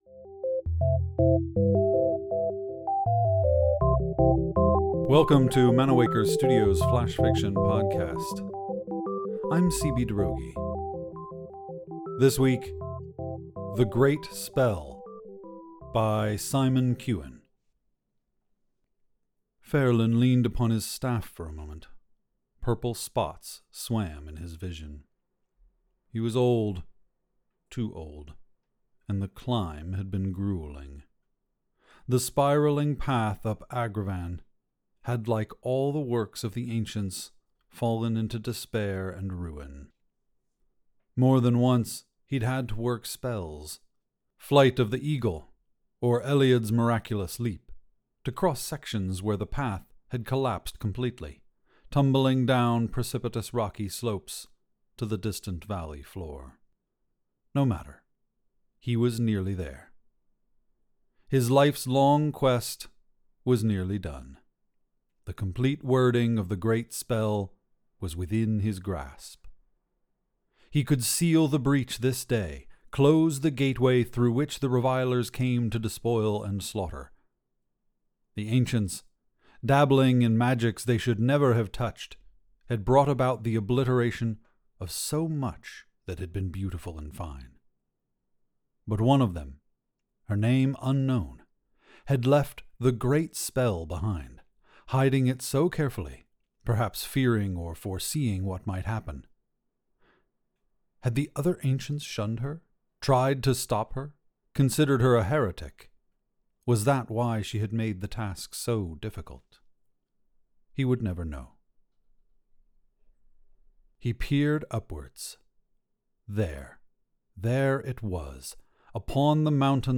The Flash Fiction Podcast Theme Song is by Kevin McCleod